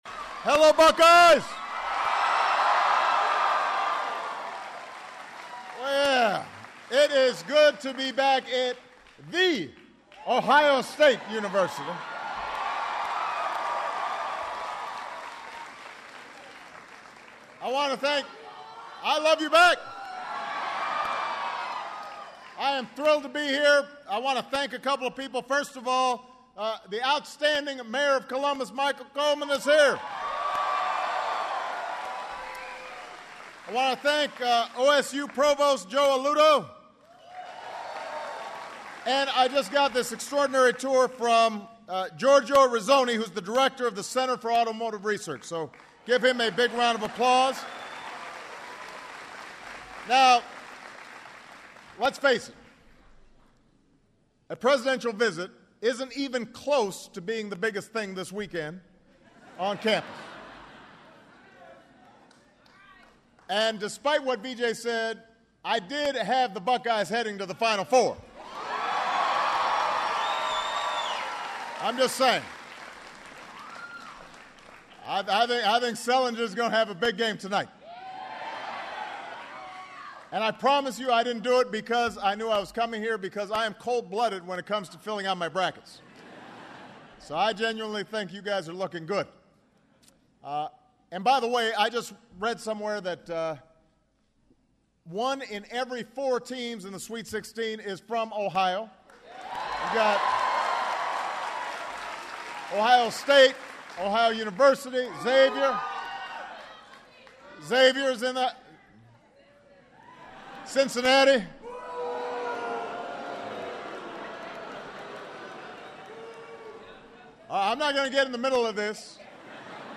President Obama discusses an "all of the above" energy strategy for America while at the Center for Automotive Research at Ohio State University.